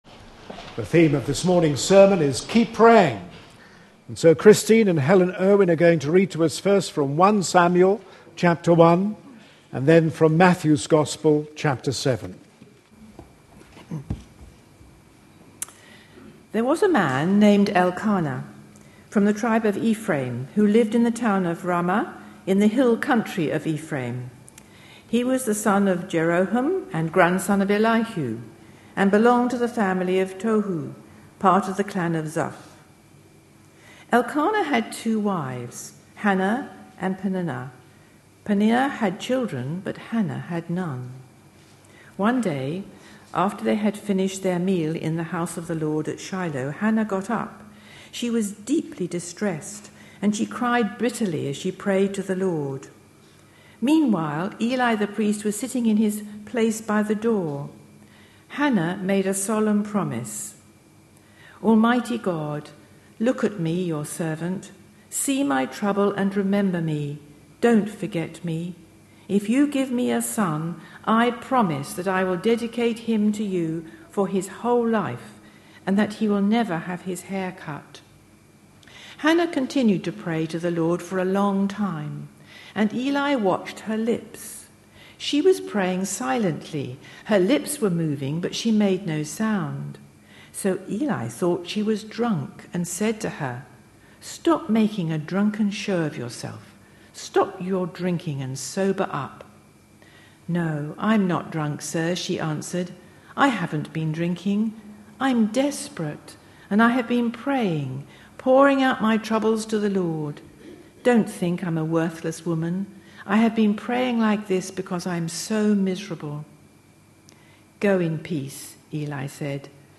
A sermon preached on 1st July, 2012, as part of our Red Letter Words series.